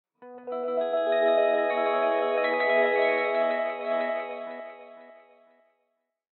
samsung-galaxy-metal-drop_24565.mp3